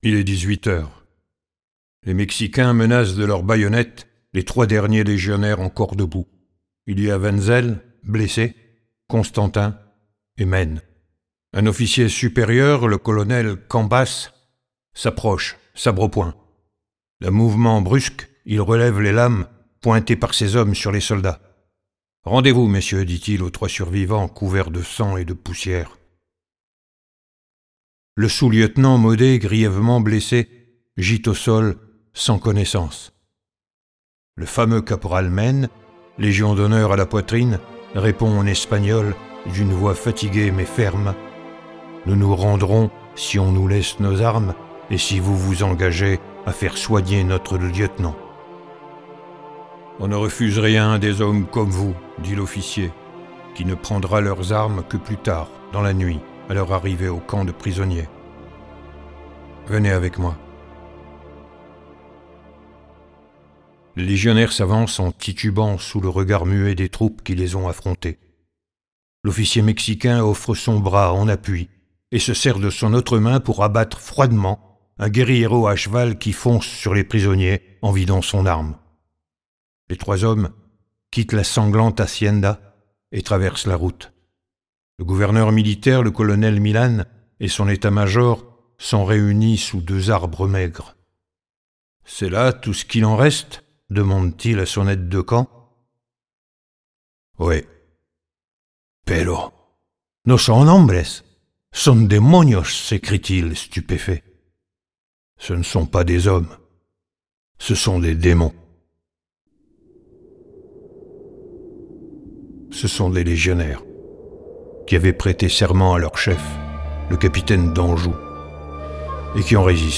Les pages oubliées de l'histoire de France : Camerone raconté par Jean-Pax Méfret